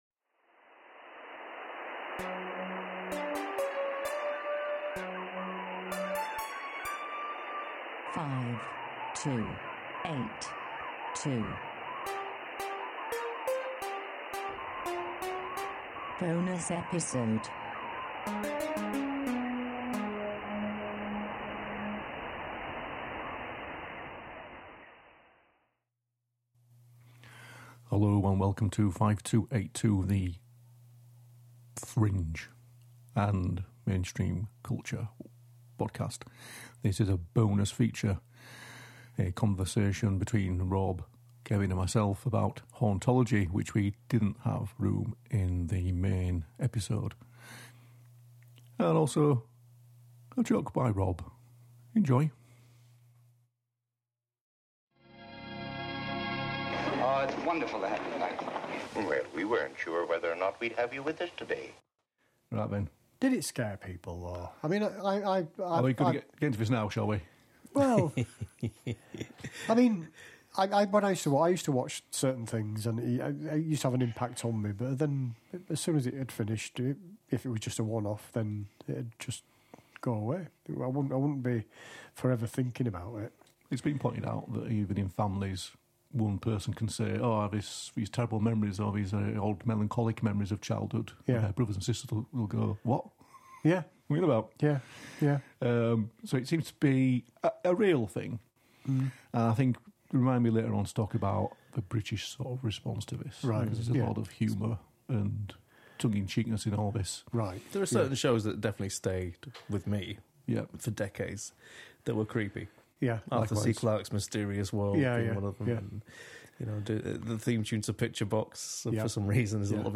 A brief chat